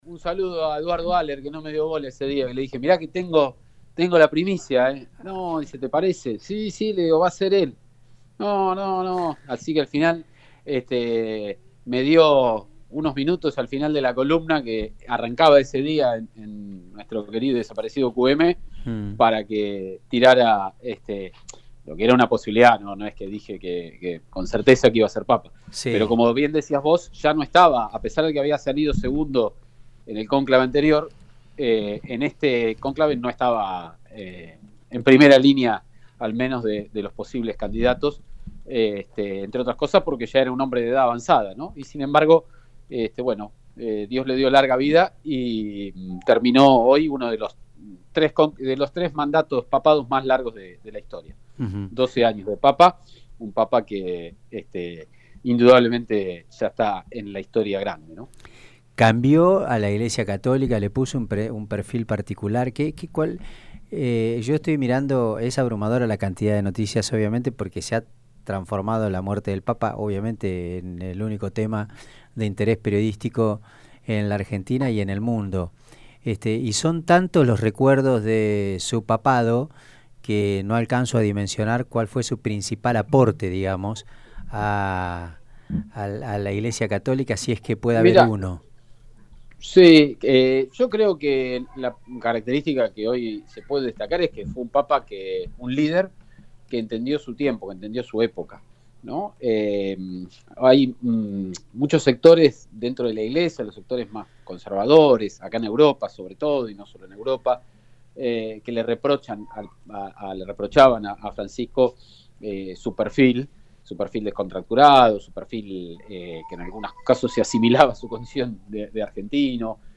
directo desde Roma, Italia.